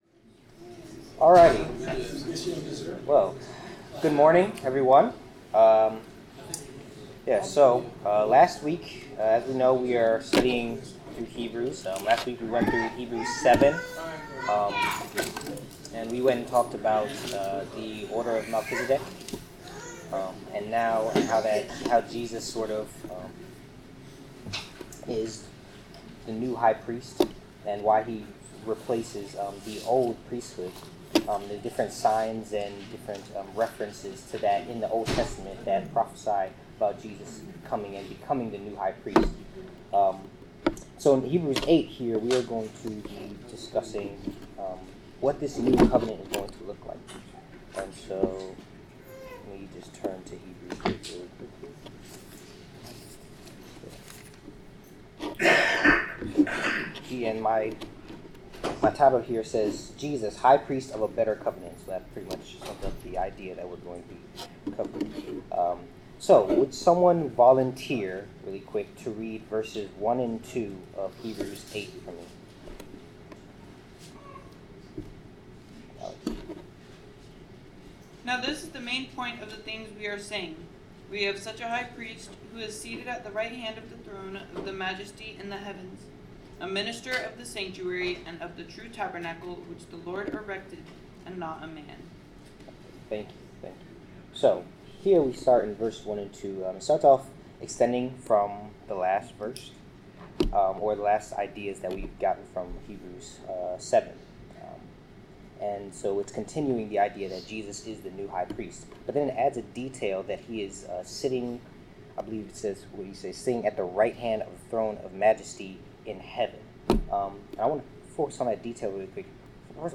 Bible class: Hebrews 8